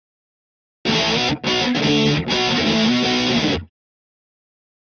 Guitar Rock Finale Rhythm Version 4